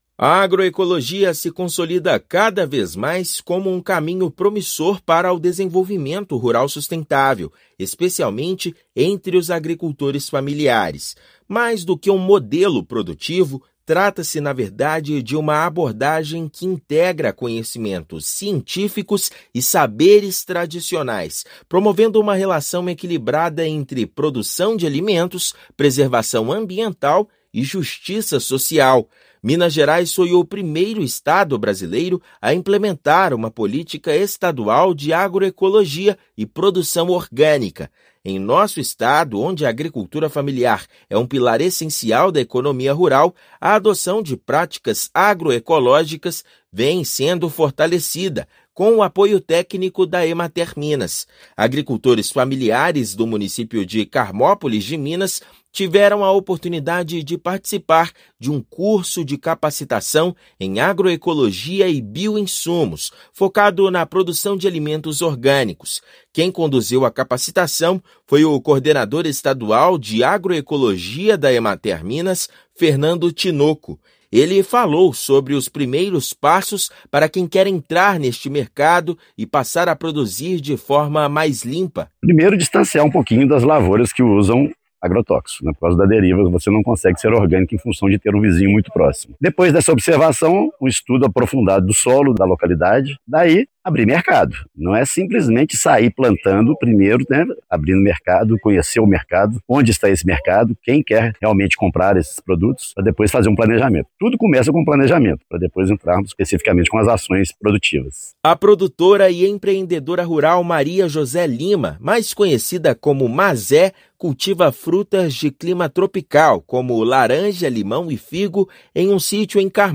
[RÁDIO] Produtores de Carmópolis de Minas apostam na agroecologia para oferecer alimentos mais saudáveis
As práticas sustentáveis são fortalecidas em Minas com apoio técnico da Emater. Ouça matéria de rádio.